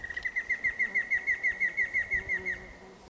Batara cinerea argentina
English Name: Giant Antshrike
Life Stage: Adult
Location or protected area: Parque Nacional Calilegua
Condition: Wild
Certainty: Recorded vocal